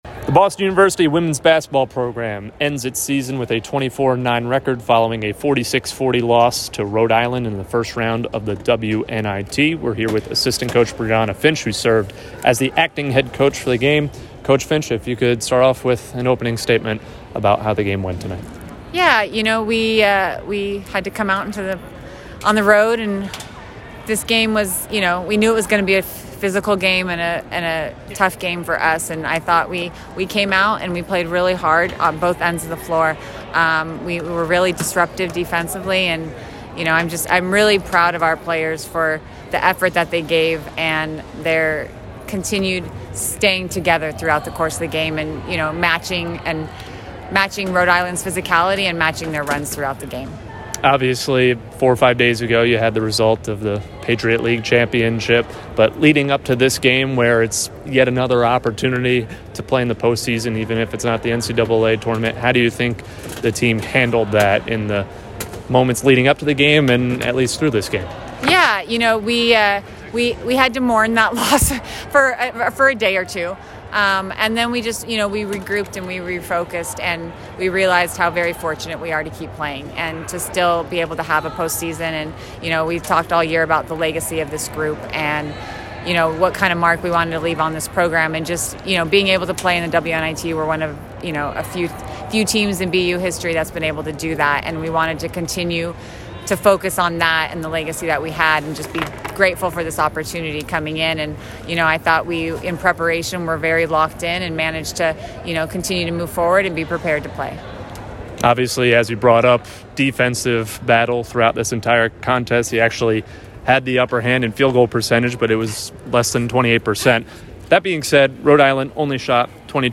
WBB_WNIT_Postgame.mp3